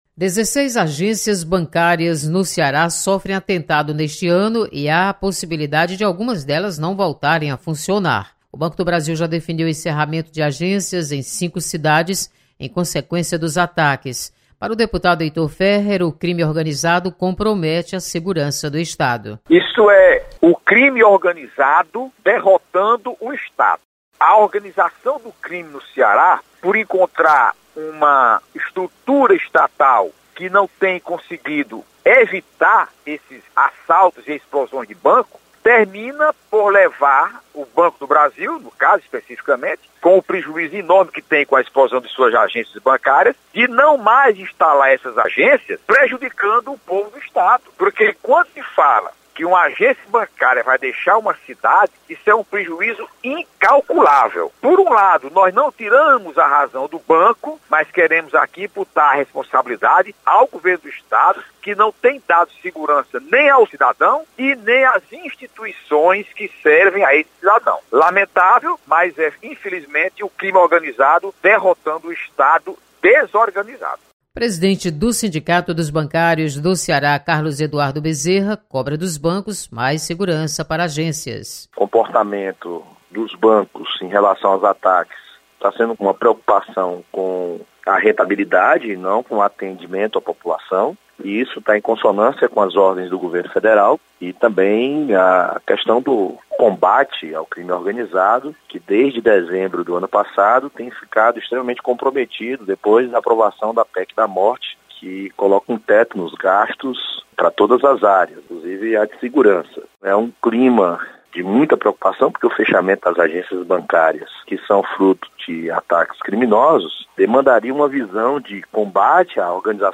Deputados mostram preocupação com segurança às agências bancárias. Repórter